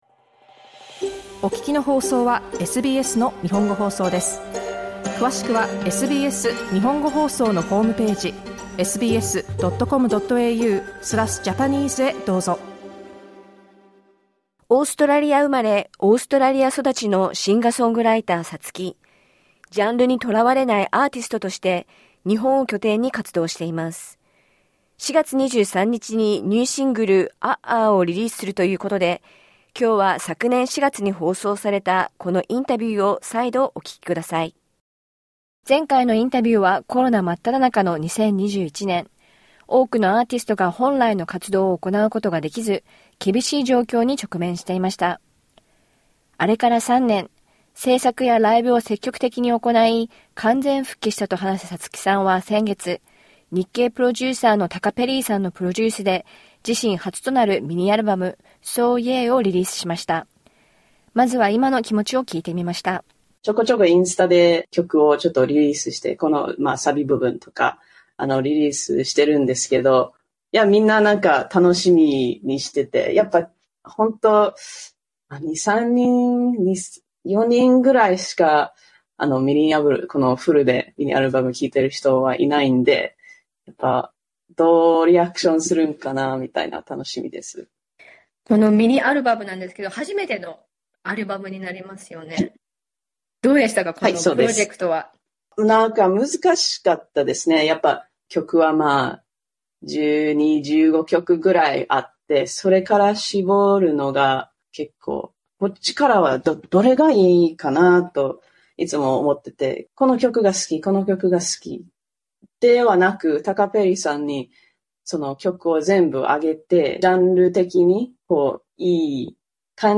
This interview was first published in April 2024.